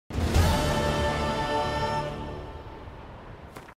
the-witcher-3-quests-completed-sound.mp3